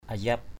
/a-zap/ (đg.) cảm thông, thương hại. mercy, pitying. ayap manuis kathaot ayP mn&{X k_E<T thương hại người nghèo. pitying the poor.